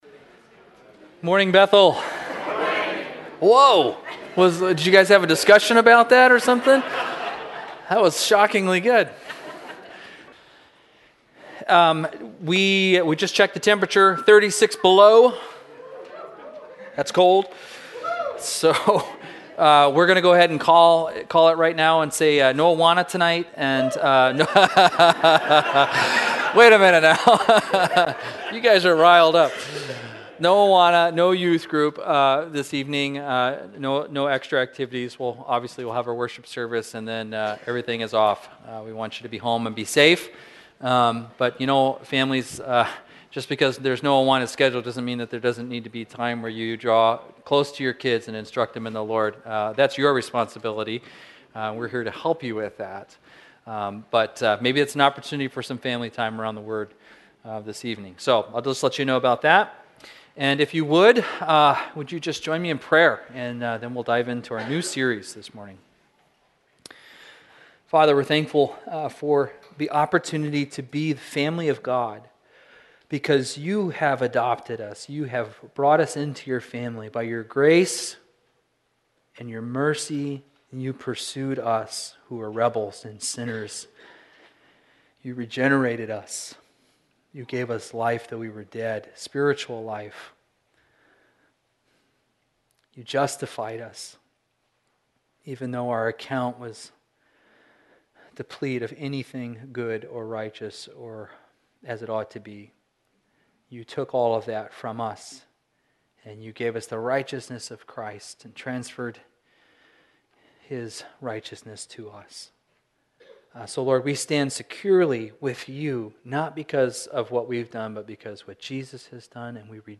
Intro To 2 Corinthians Bethel Church - Fairbanks, AK Sermons podcast